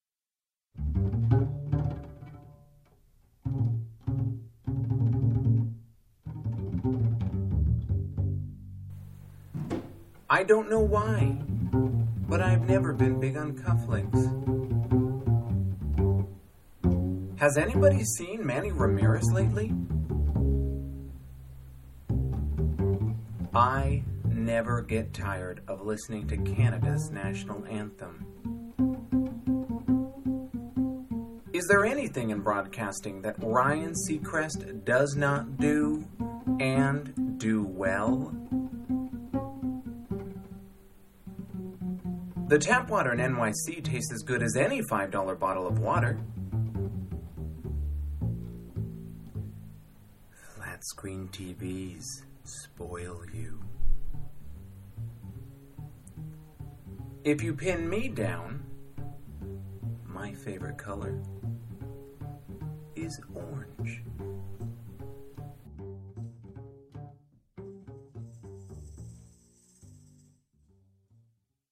Download my spoken word version with accompaniment by Charles Mingus: